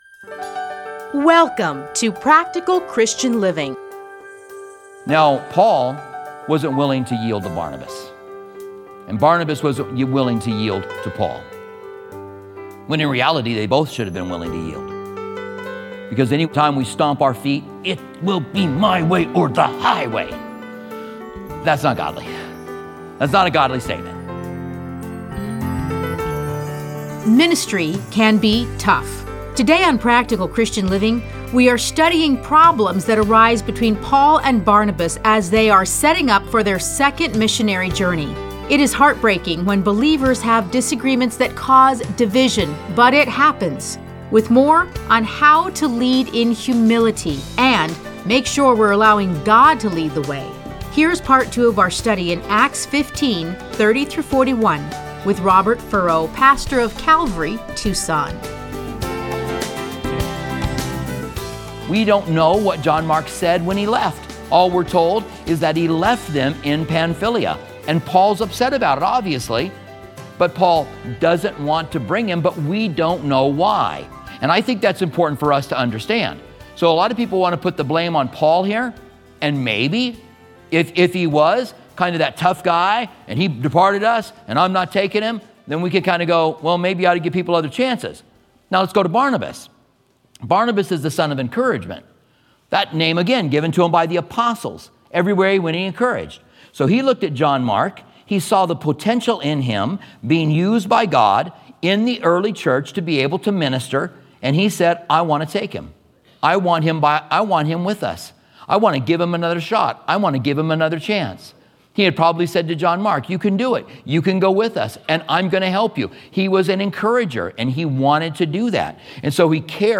Listen to a teaching from Acts 15:30-41.